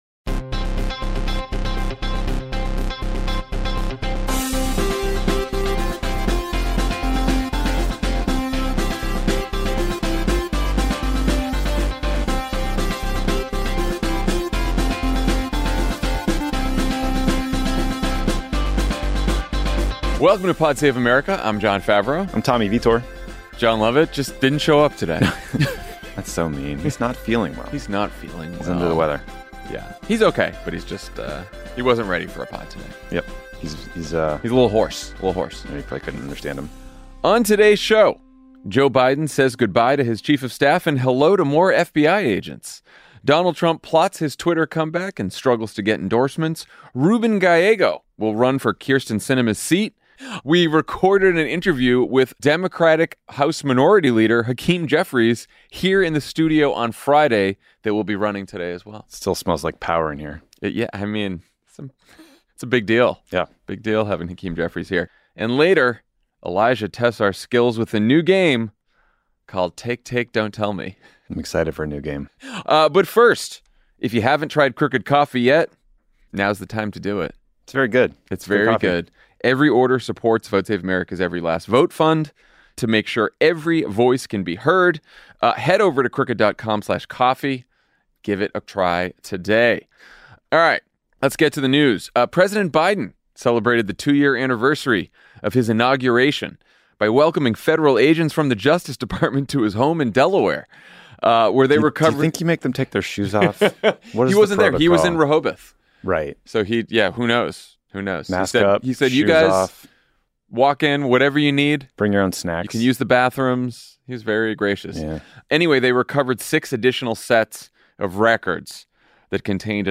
House Minority Leader Hakeem Jeffries stops by Crooked HQ to talk about the upcoming year in Congress. And the guys play a new game called Take Take Don’t Tell Me.